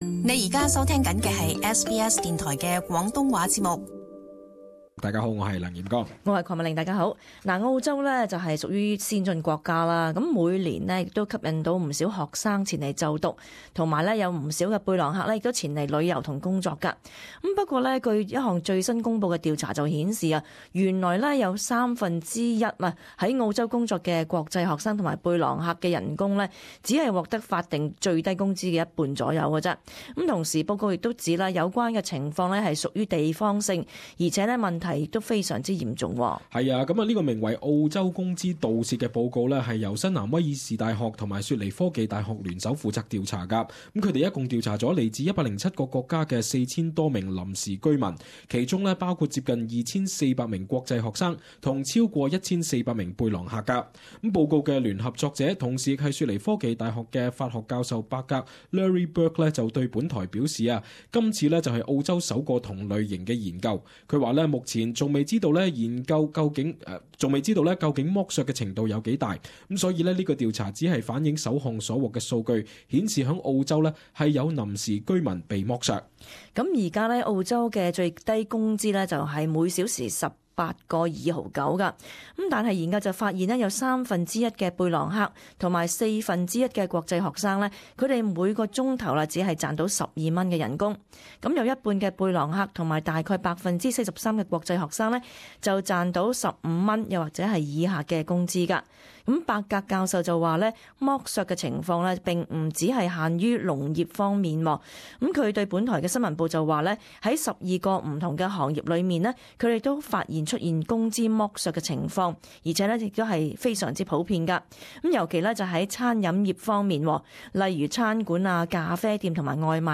【時事報導】： 國際學生及背囊客工資遭剝削